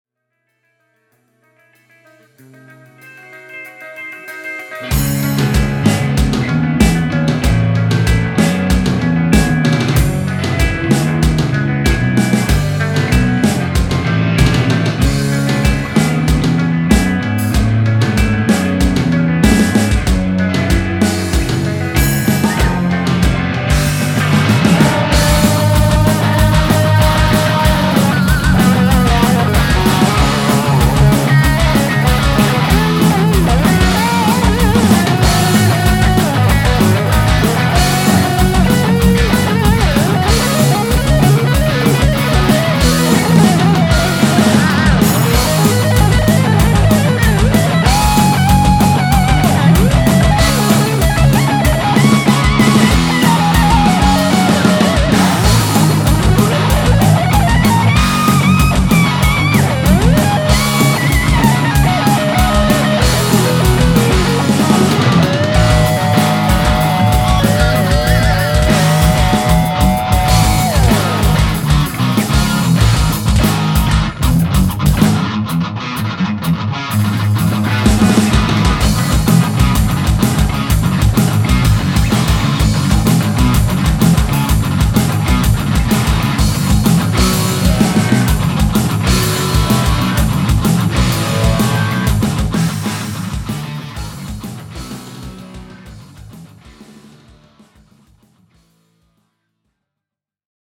"metal" mix, bass, drums and others